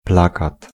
Ääntäminen
Synonyymit poster Ääntäminen France: IPA: /a.fiʃ/ Haettu sana löytyi näillä lähdekielillä: ranska Käännös Ääninäyte 1. plakat {m} 2. afisz {m} Suku: f .